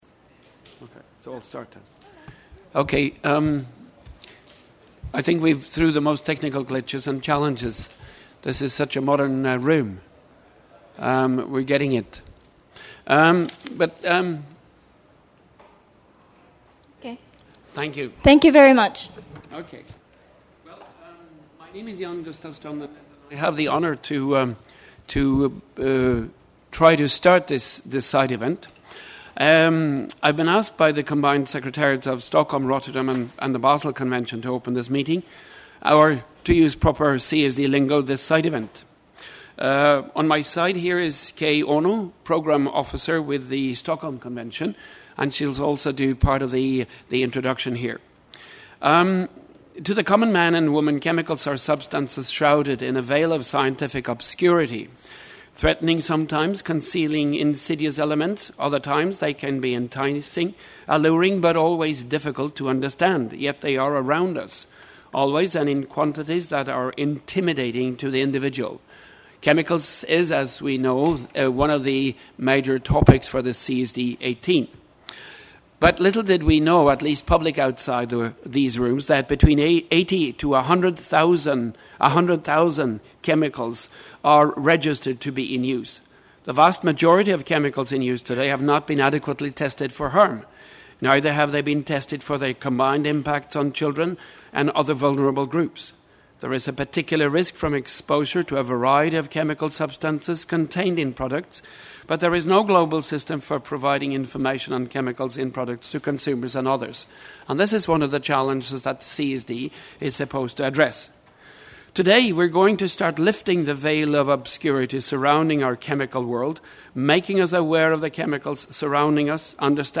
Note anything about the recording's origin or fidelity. CSD-18 Side Event to launch the “Safe Planet Campaign”, a global awareness campaign on chemicals and waste csd18_side_event_chemicals_waste.mp3